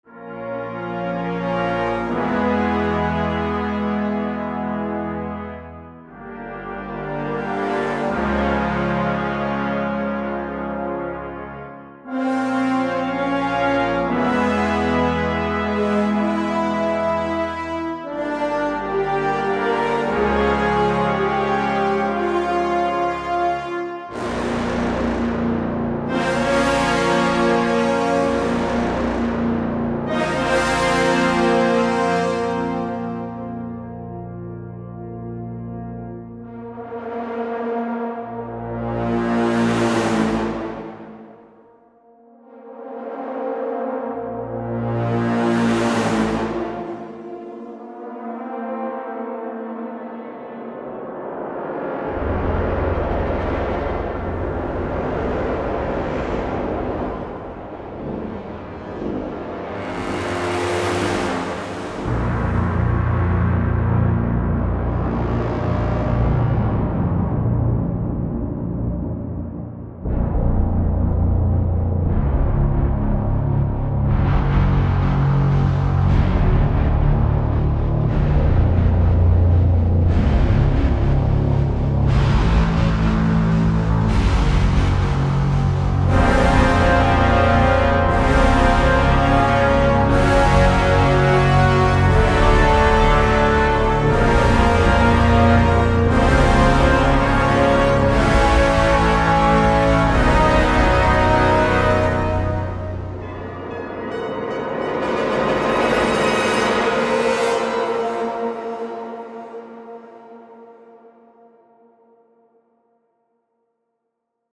A NEW BENCHMARK FOR ORCHESTRAL BRASS